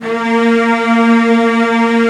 CELLOS CN4-R.wav